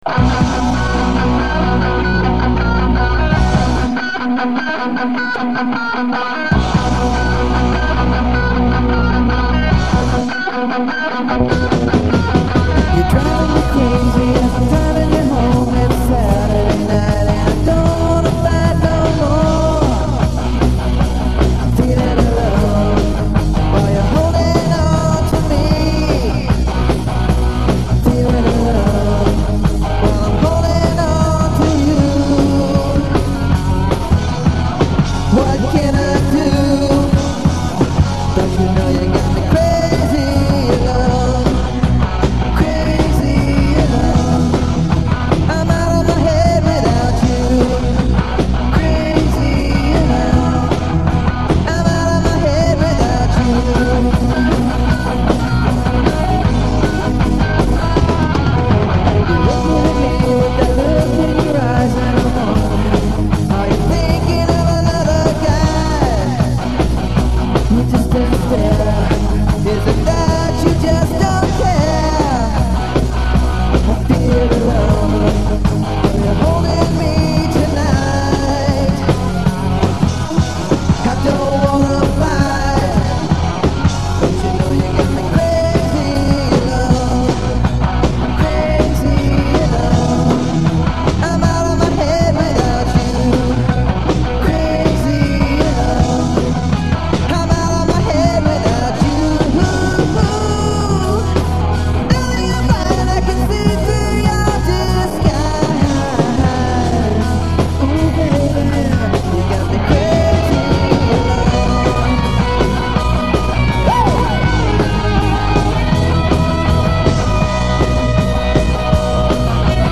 "Garage" Sessions
Vocals, Guitar
Bass
Drums